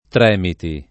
Tremiti [ tr $ miti ]